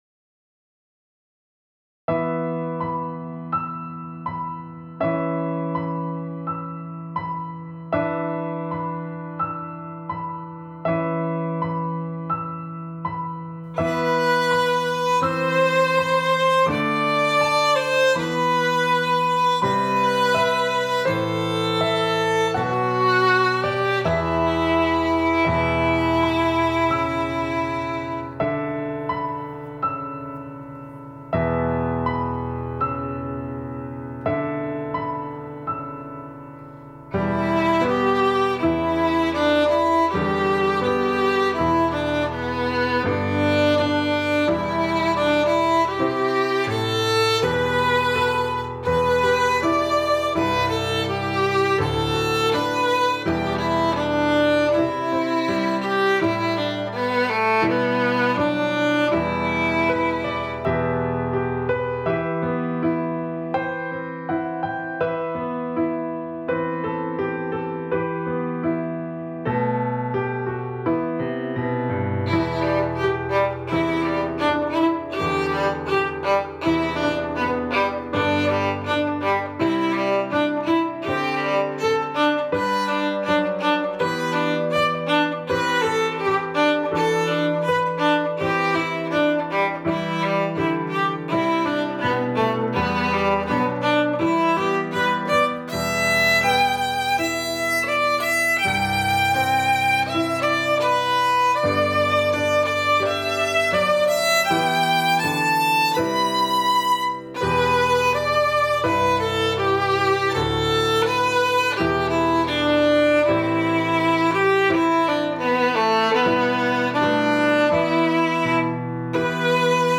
A beautiful arrangement for strings with piano
Instrumentation: Violin, Viola, Cello, Bass, Piano